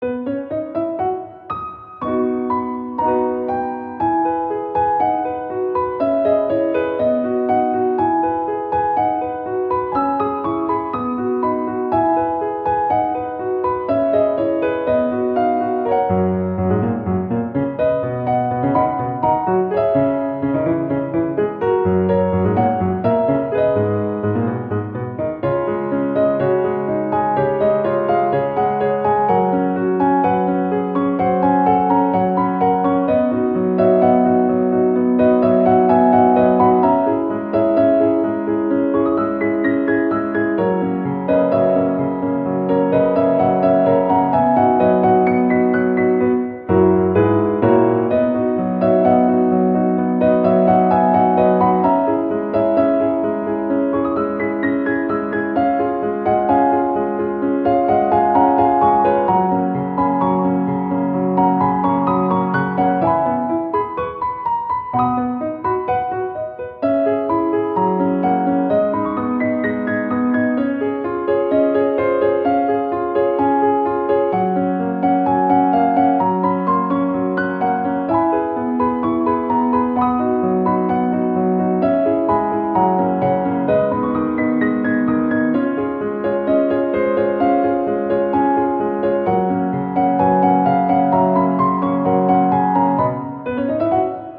• 明るくほがらかなピアノ曲のフリー音源を公開しています。
ogg(L) - 希望 ほがらか 明るい